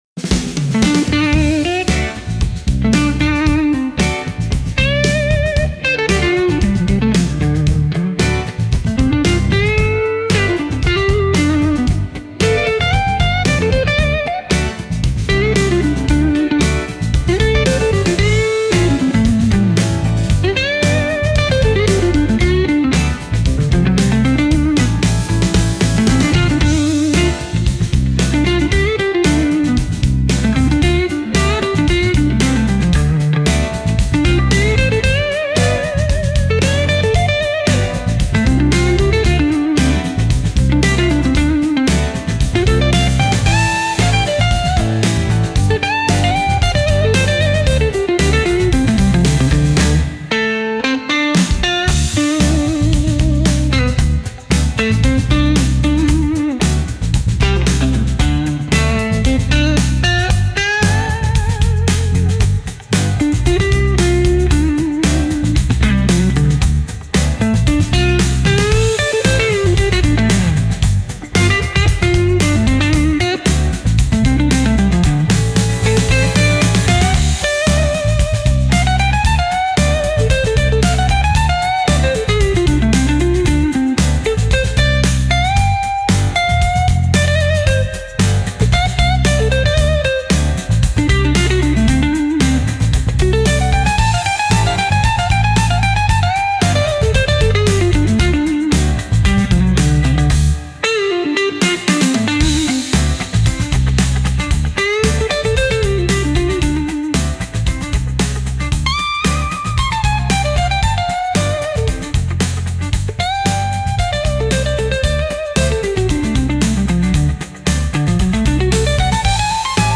Instrumental - Real Liberty Media Dot XYZ- 4.00 mins.mp3